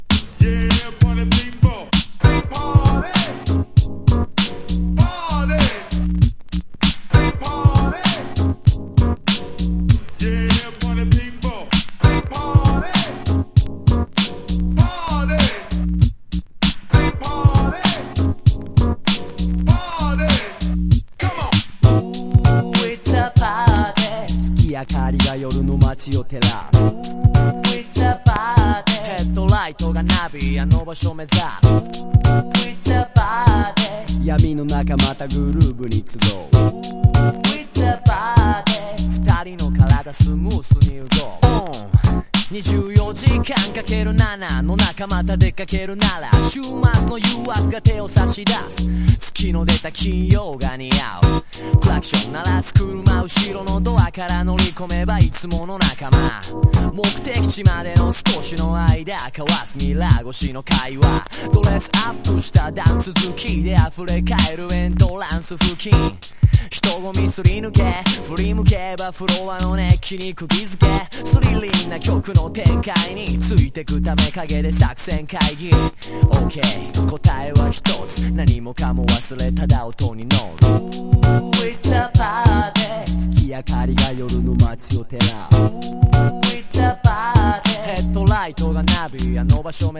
• HIPHOP